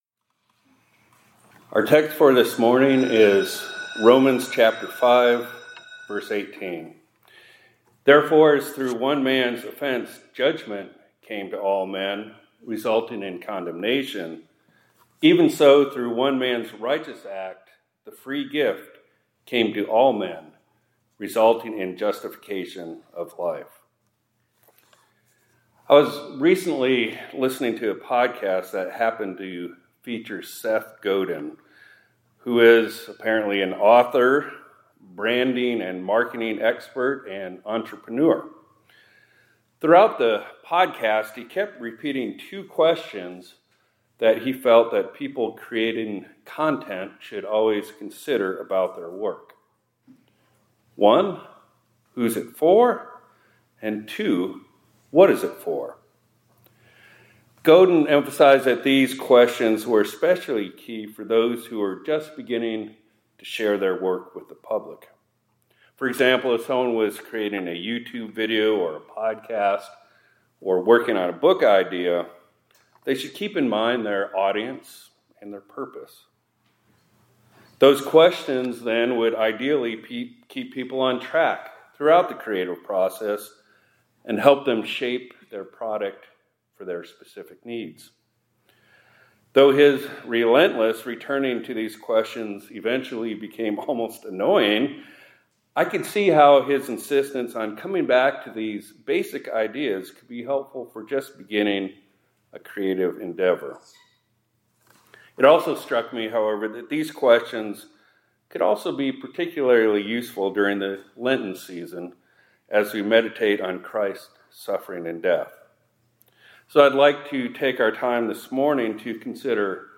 2026-03-24 ILC Chapel — Two Questions on Christ’s Passion